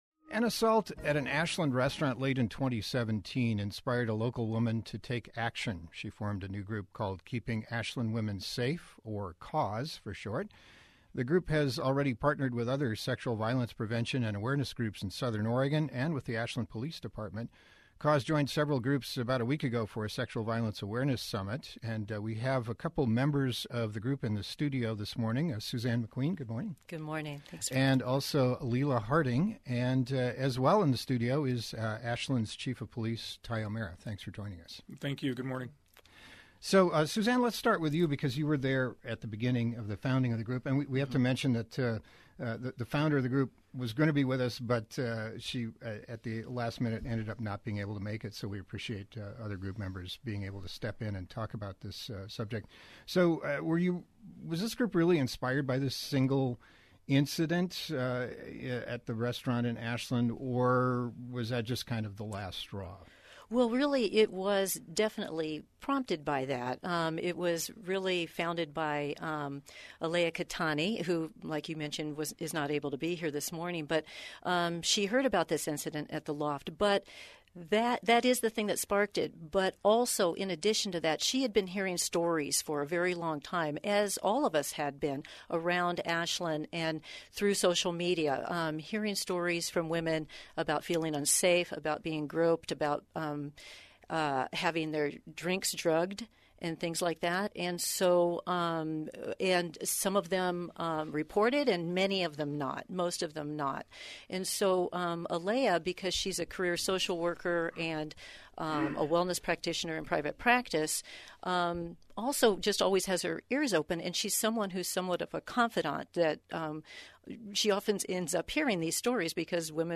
This is a radio interview that I did along with others who belong to a new taskforce in my town.